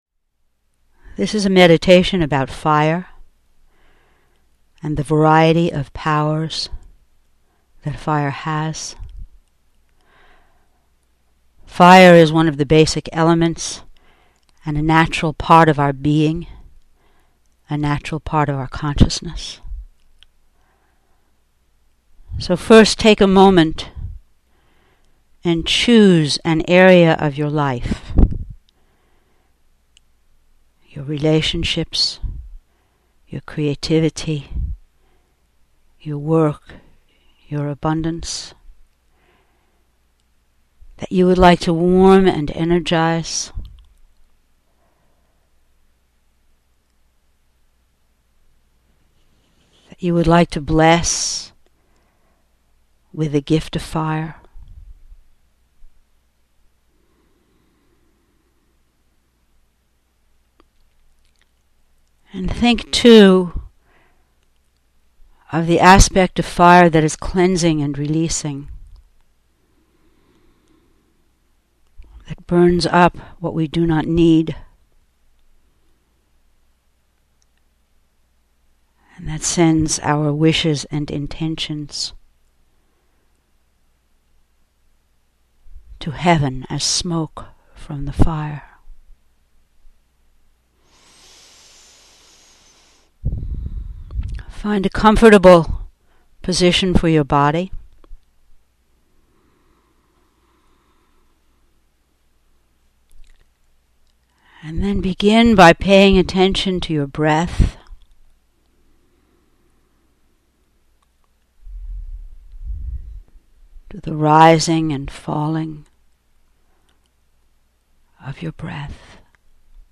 Audio Meditations